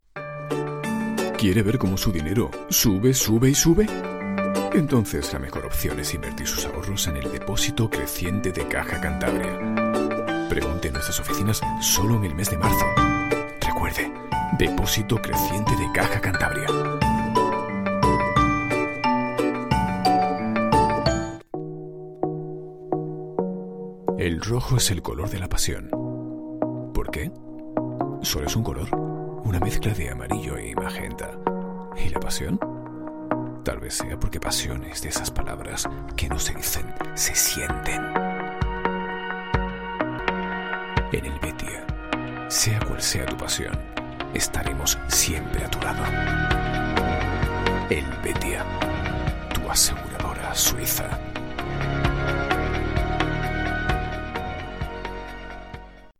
voz cálida, envolvente, elegante, transmite confianza, seguridad, seriedad con sentido de humor, versátil, seductora
Sprechprobe: Werbung (Muttersprache):
Totales publicidad.mp3